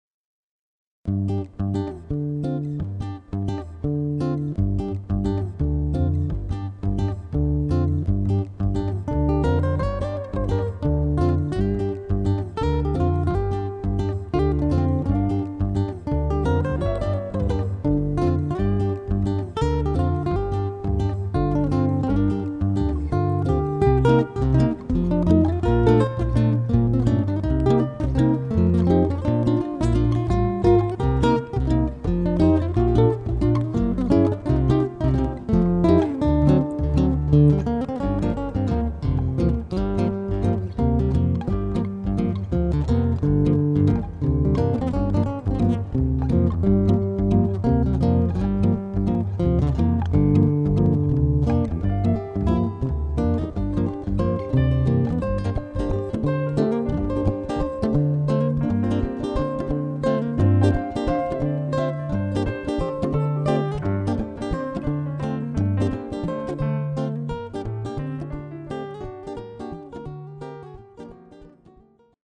when I was doing more multitrack sessions
which were recorded on a Yamaha Silent Guitar